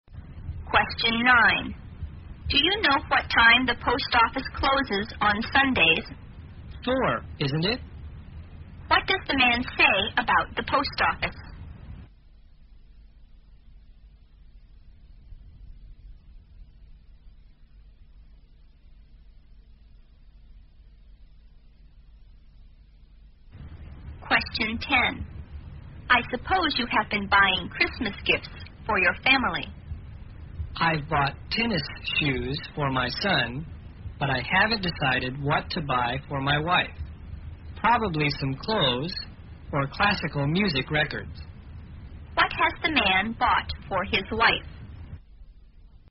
在线英语听力室174的听力文件下载,英语四级听力-短对话-在线英语听力室